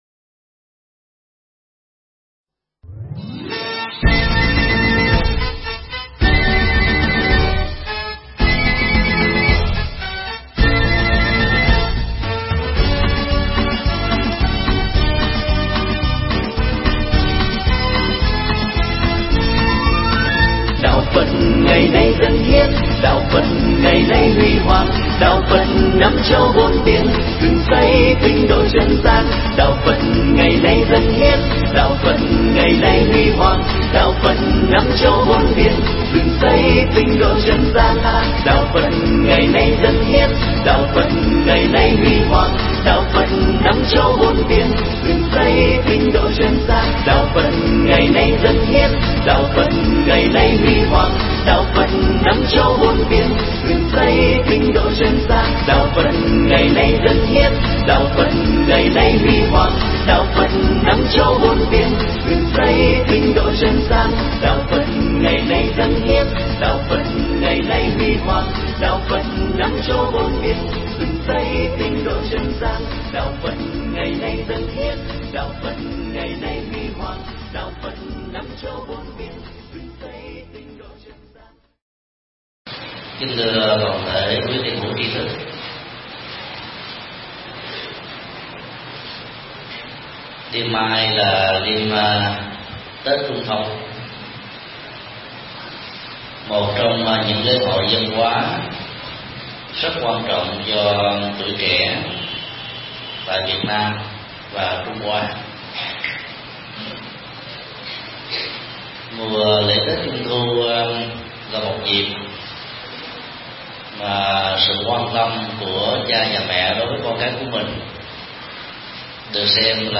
Thuyết pháp Ý nghĩa tết Trung thu
giảng tại Chùa Giác Ngộ